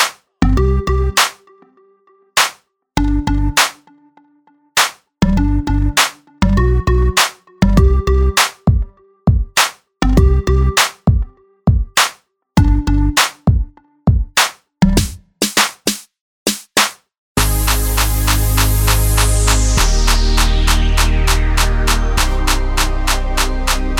For Solo Female Reggae 3:43 Buy £1.50